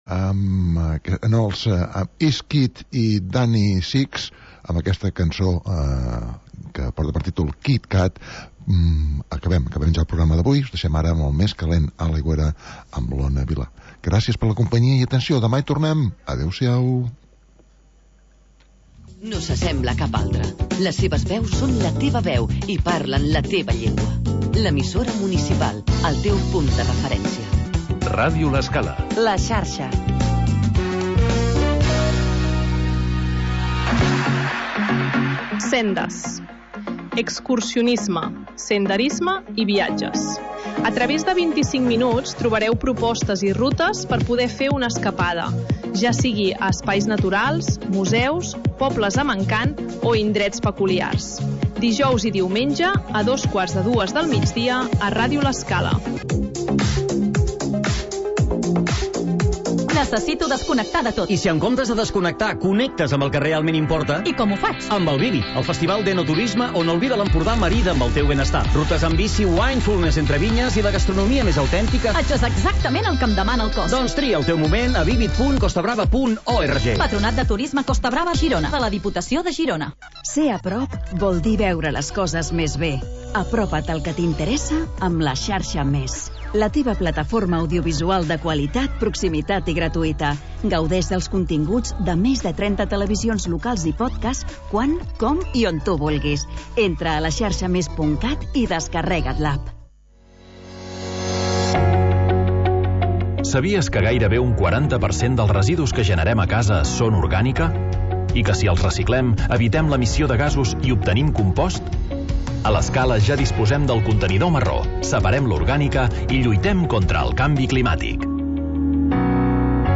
Magazín d'entreteniment per acompanyar el migdia